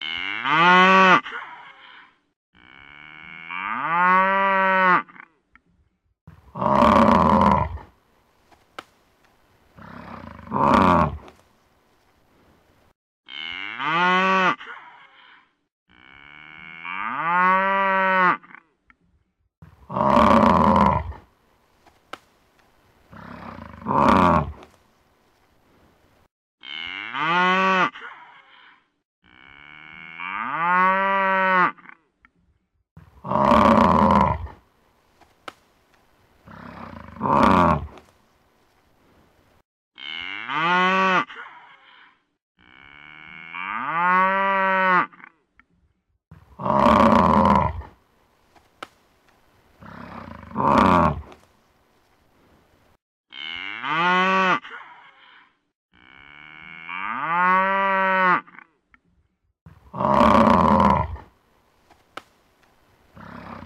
Tiếng Trâu Kêu
Tiếng động vật 284 lượt xem 10/03/2026
Download tiếng trâu kêu mp3, tải miễn phí hiệu ứng tiếng con trâu kêu mp3 hay nhất, chuẩn nhất.